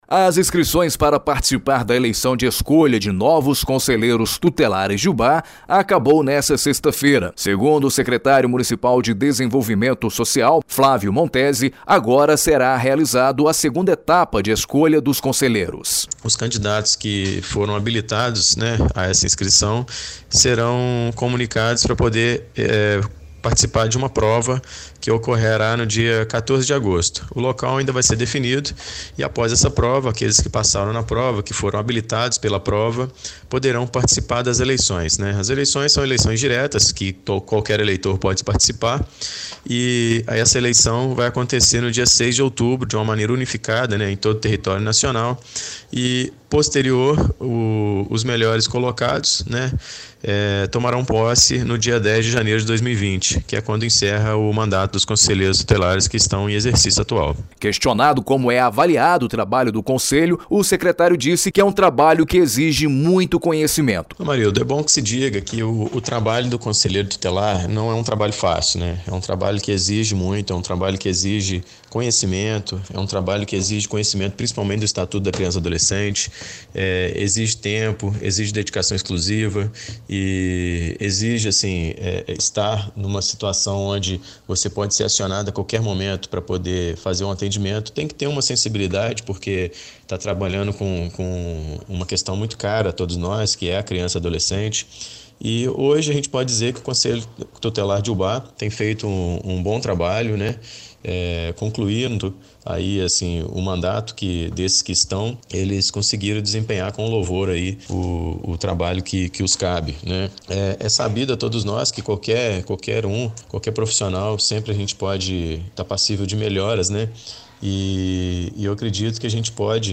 Secretário Municipal de Desenvolvimento Social Flávio Montezze
Reportagem exibida na Rádio Educadora Ubá – MG